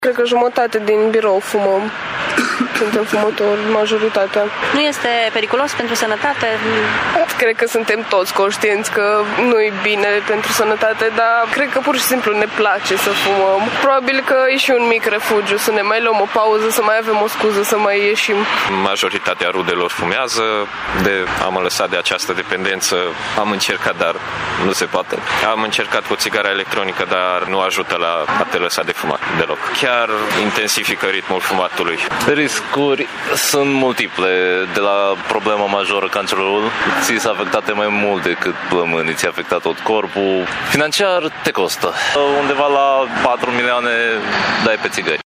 Deși majoritatea sunt tineri, aceștia recomandă celor care încă nu s-au apucat de fumat să nu repete greșeala lor: